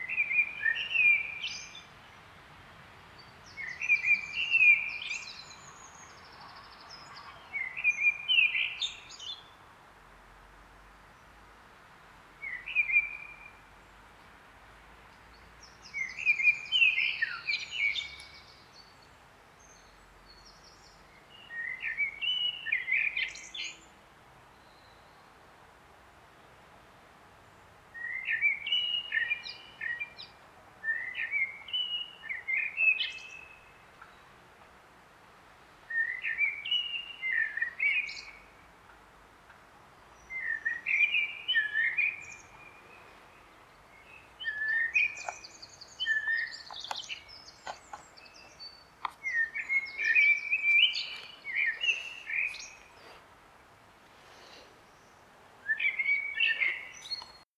Ce matin, dans le noir les oiseaux chantaient…